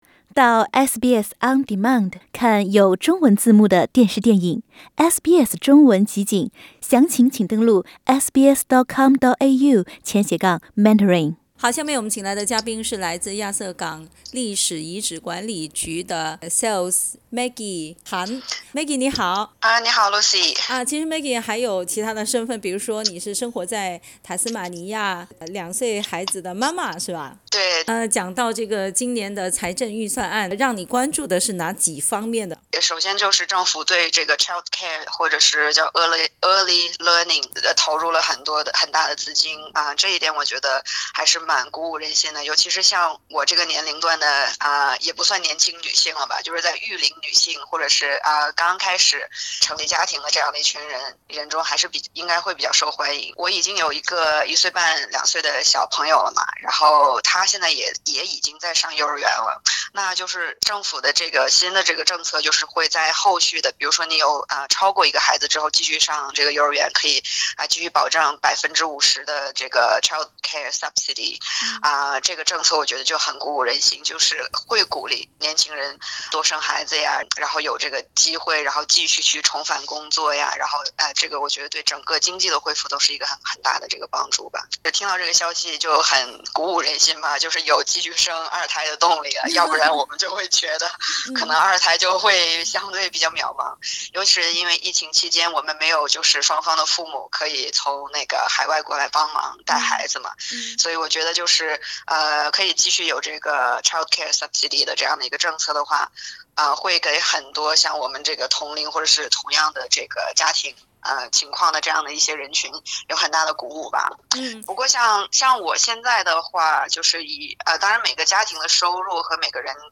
（本节目为嘉宾观点，请听采访） 澳大利亚人必须与他人保持至少1.5米的社交距离，请查看您所在州或领地的最新社交限制措施。